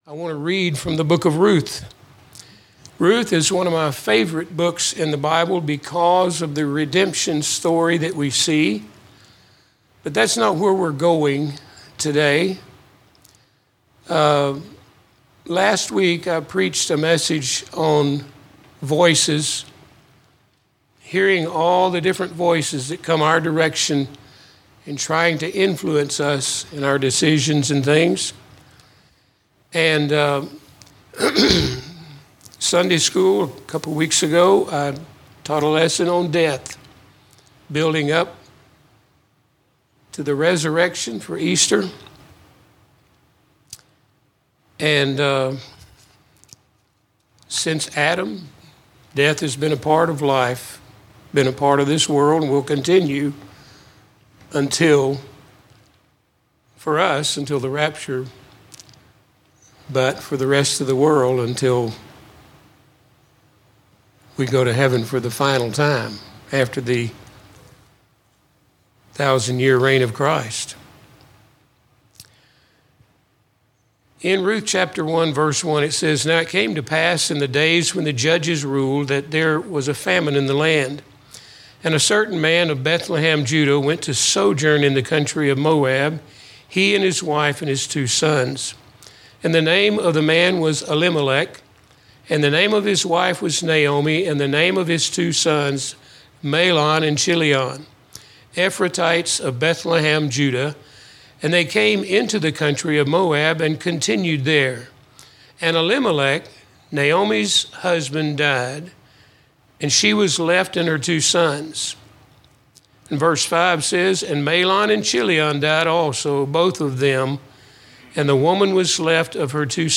Sermons - Emmanuel Baptist Church
From Series: "General Preaching"